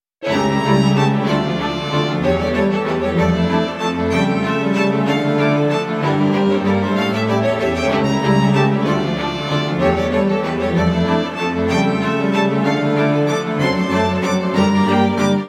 3. 管弦乐
对三种连奏模式进行了采样 - 含糊不清、弓形和波塔门托。
这是演奏者以尖锐的渐强和快速加重音调的弓声结束音符的声音。